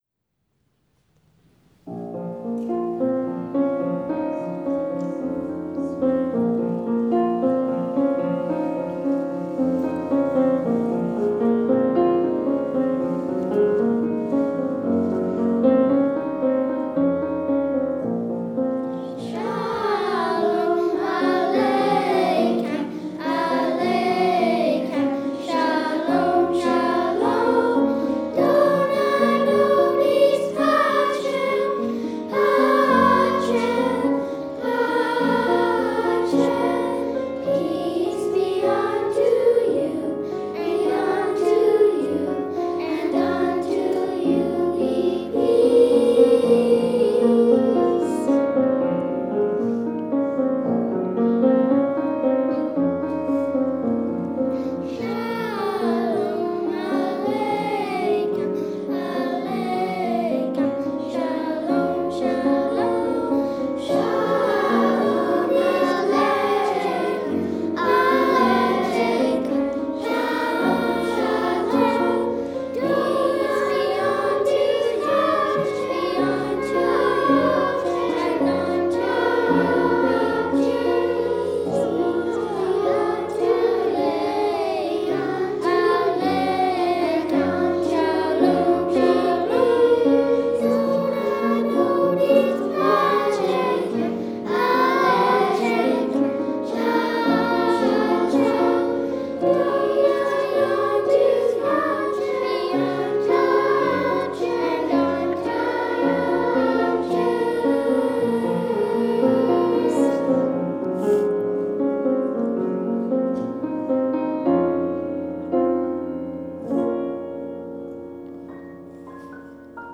Recording Location: James Bay United Church, Victoria BC
Status: Raw, unedited
The 20-member children's chorus
128kbps Stereo